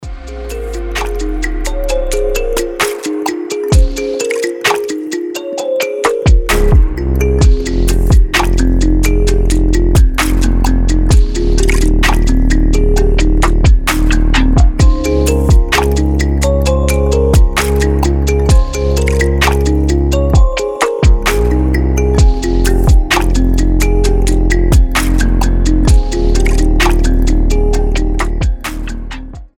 Электроника
без слов